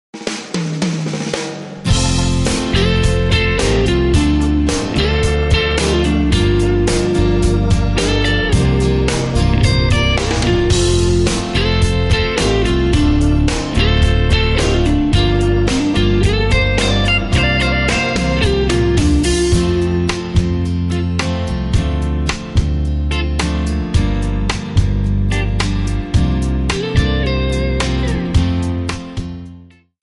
Backing track files: Country (2471)